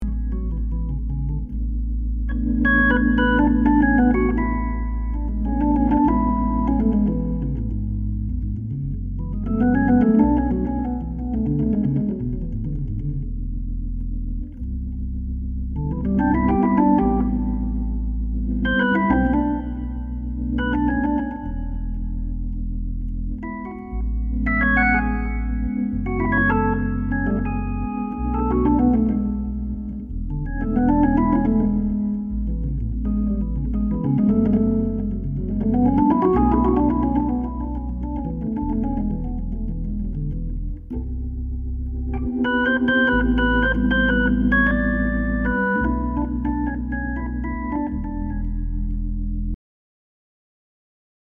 full drawbar ahead !!!